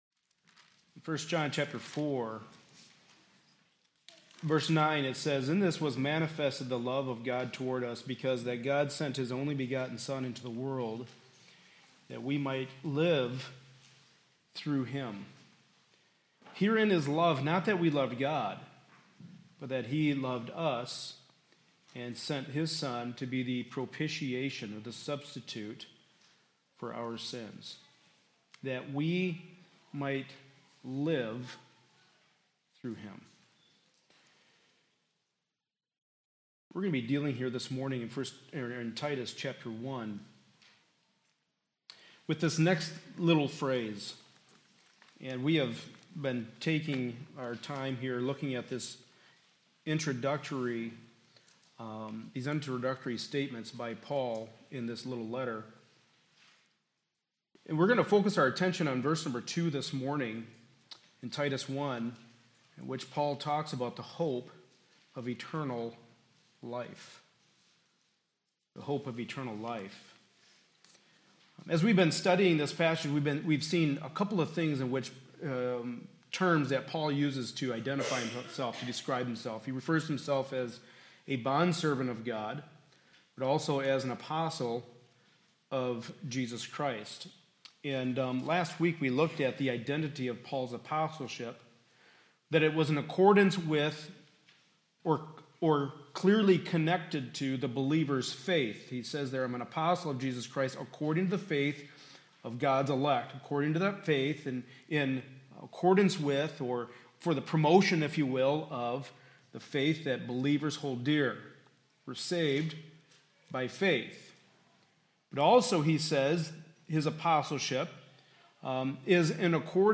Series: The Book of Titus Passage: Titus 1:1-4 Service Type: Sunday Morning Service